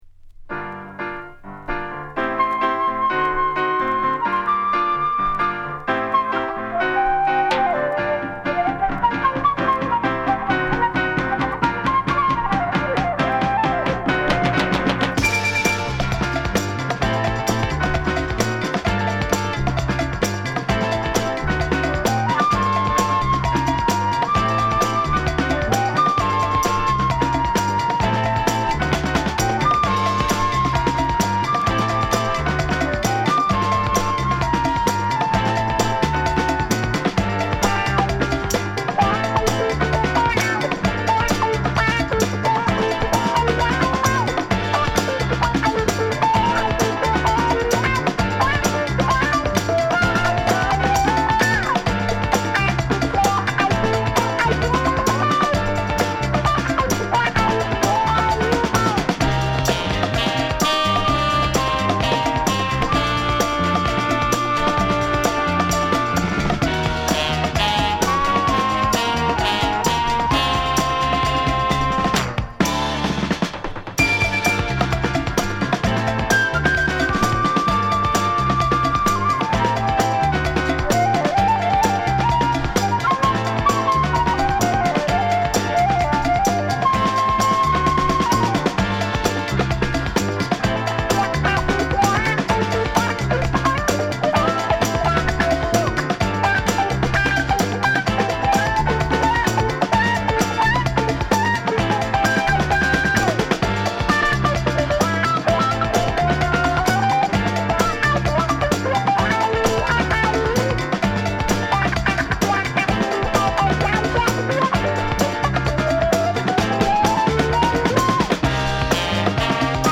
ピアノとフルートが絡むイントロからグッとクル、文句ナシにカッコいい1曲。